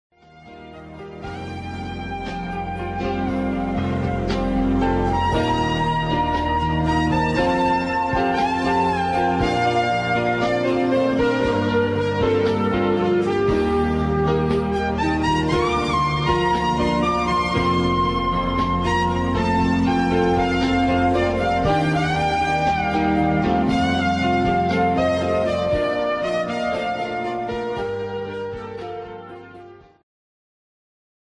Segment Progressive
Rock